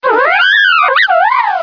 Sounds / Cries